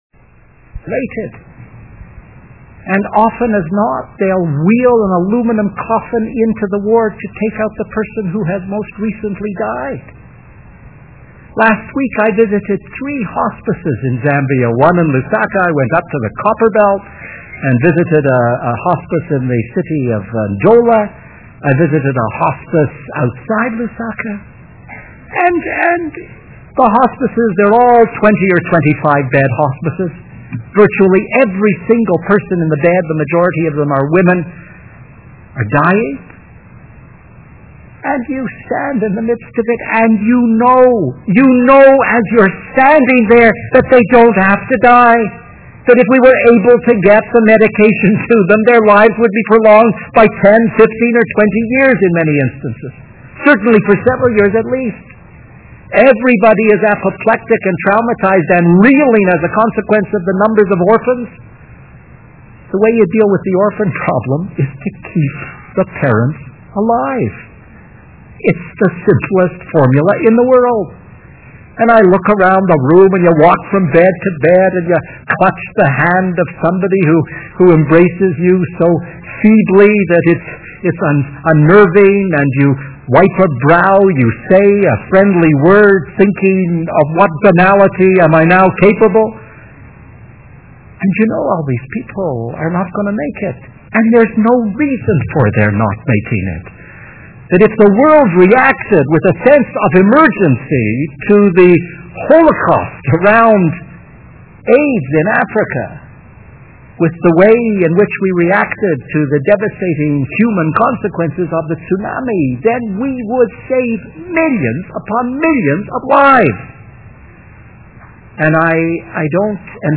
All-Conference Plenary Session Stephen Lewis